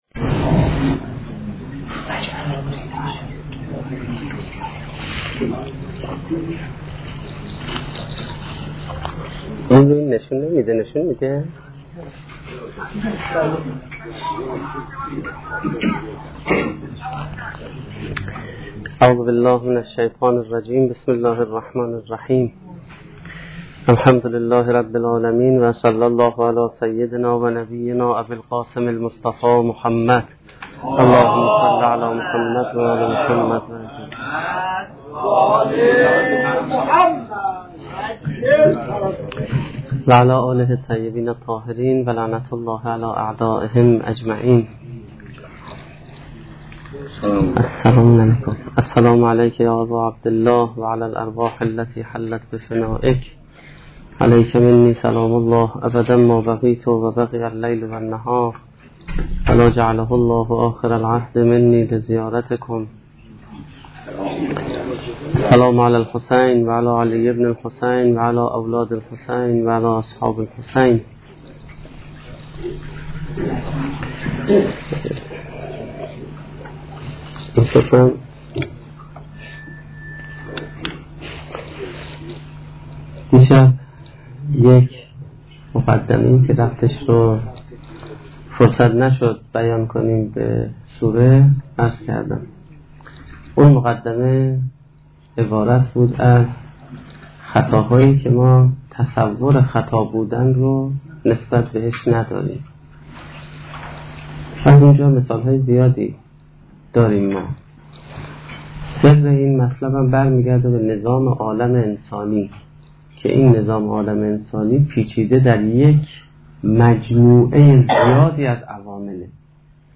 سخنرانی دومین شب دهه محرم1435-1392